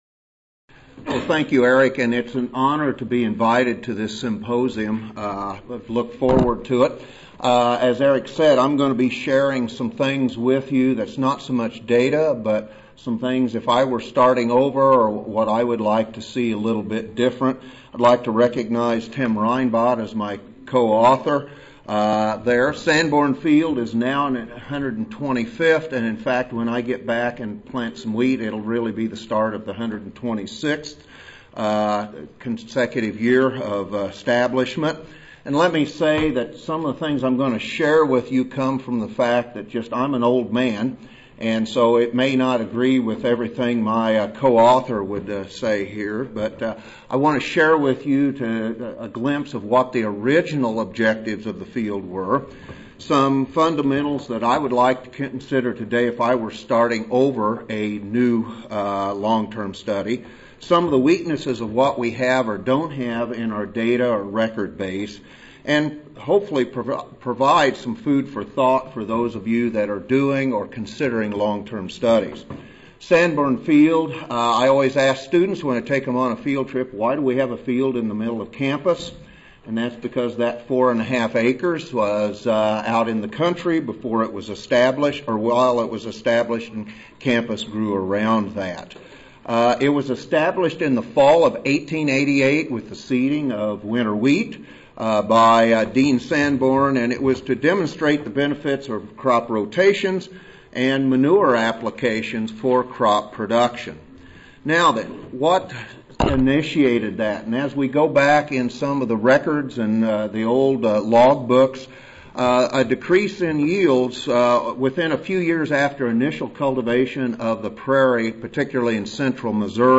University of Missouri Audio File Recorded Presentation